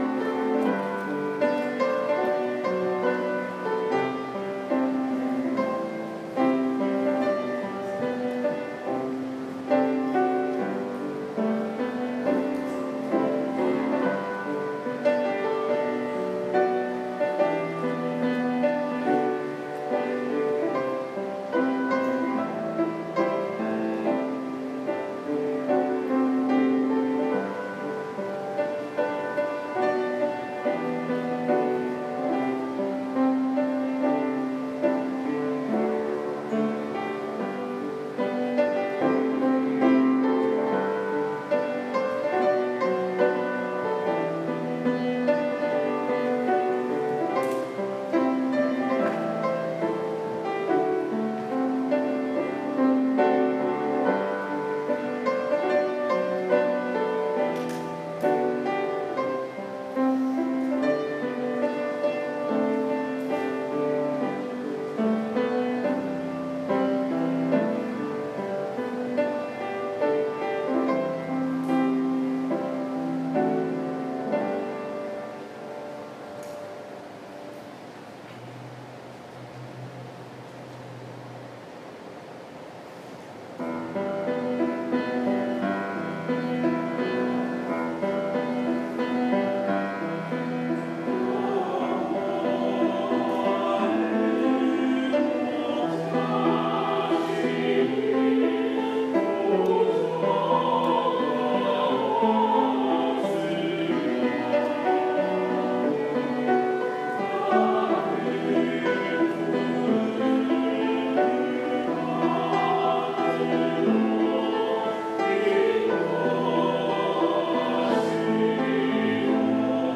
7월 17일 주일 찬양대 찬양(여호와는 나의 목자시니)